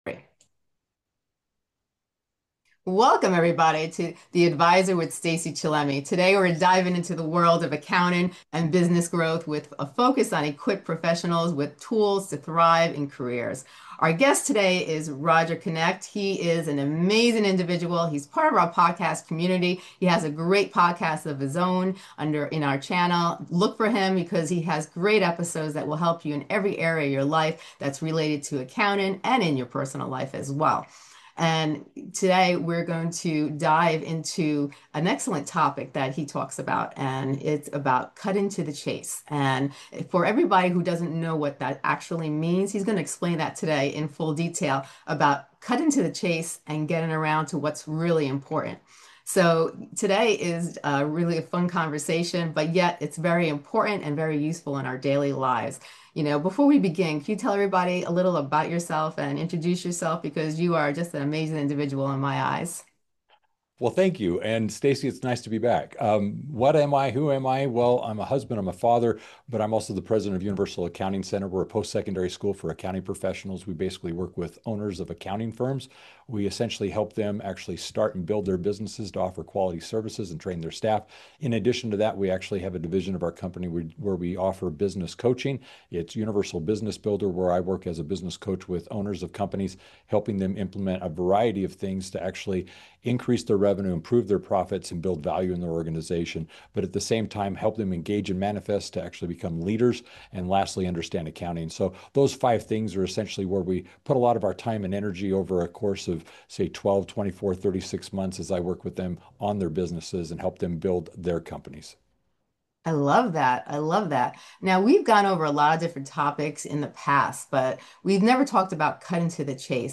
From identifying financial blind spots to building systems that drive predictable success, this conversation is packed with practical tips you can implement immediately.